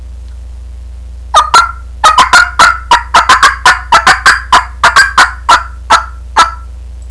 Cuttcall.wav